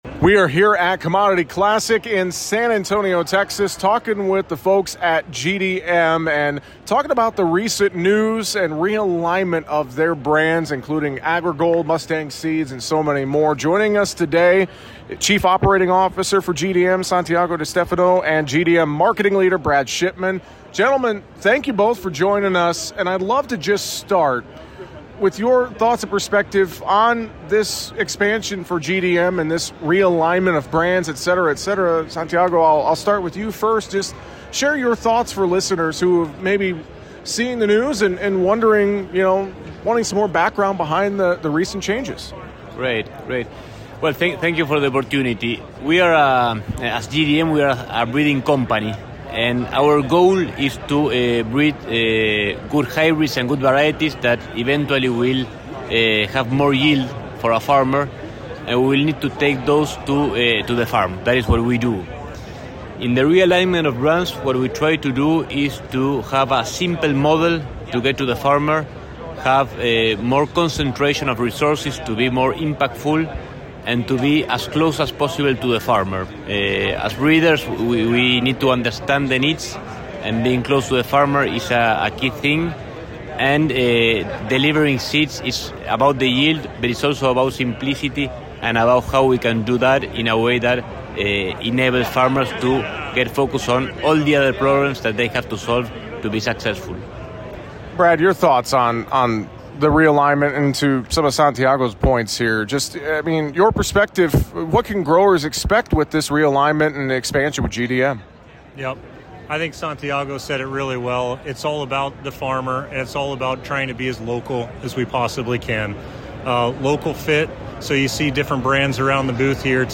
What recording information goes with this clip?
during the 2026 Commodity Classic.